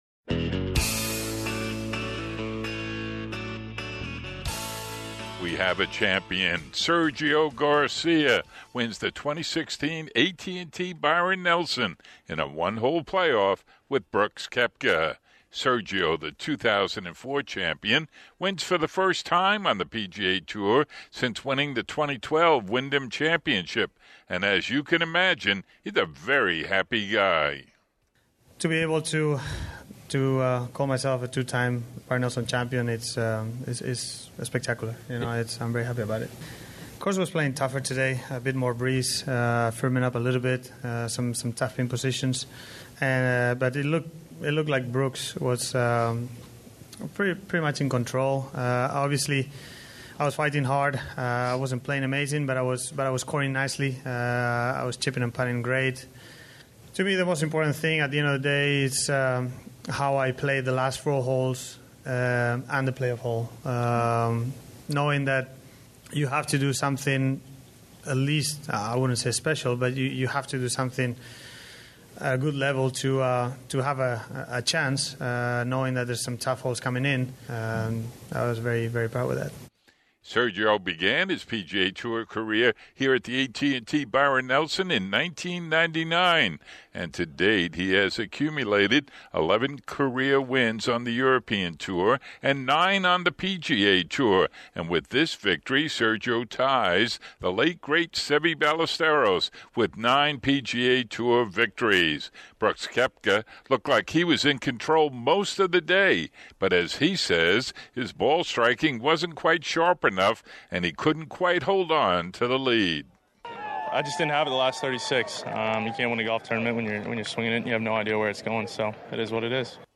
Sergio Garcia has won the 2016 AT&T Byron Nelson after a one hole playoff with Brooks Koepka. Understandably Sergio says he's very happy. When Byron Nelson was 91 years old he said how proud he was to be associated with the Salesmanship Club of Dallas and we hear it in his own words.